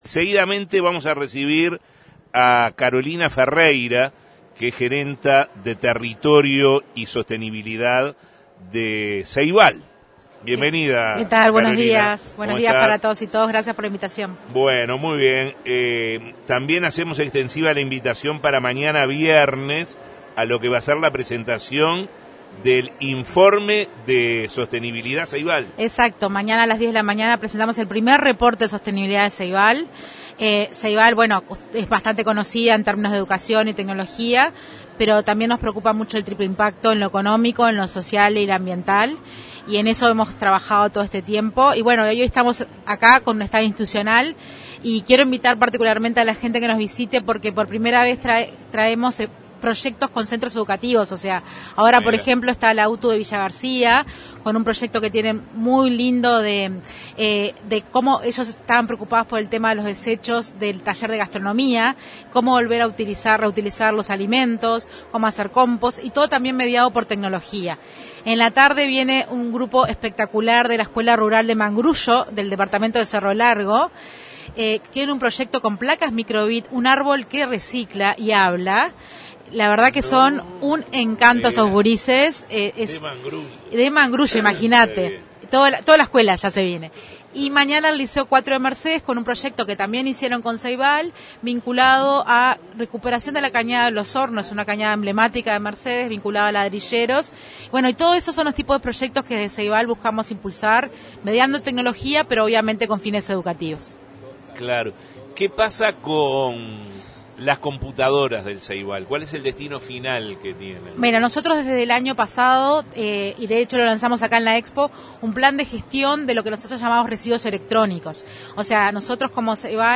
Programa especial de Justos y pecadores desde la Expo Uruguay Sostenible.